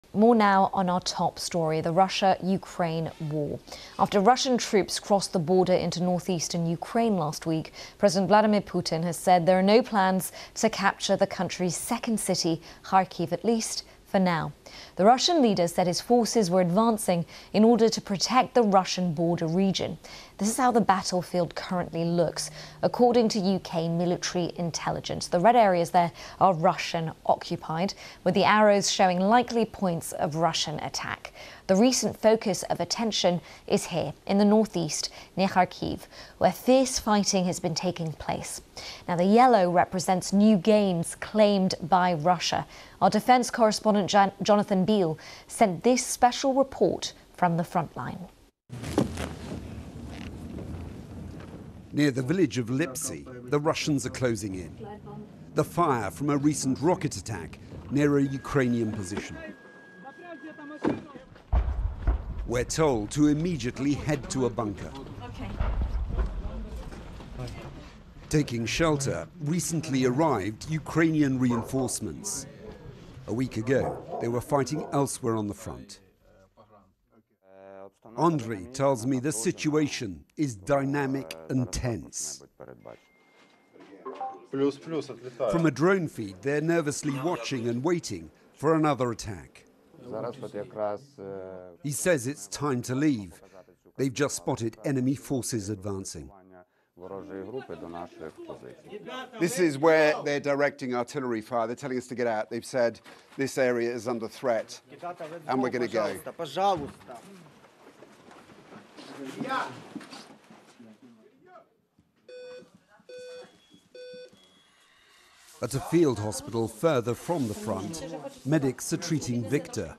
Audio news 'Ukraine front line near Kharkiv'.